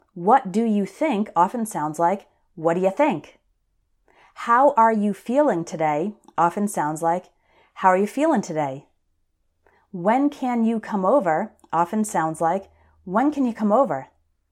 One reason for the difficulty is that when native English speakers are talking fast, we often change how we pronounce the words at the beginning of questions.
These pronunciation changes also happen when the question starts with a question word: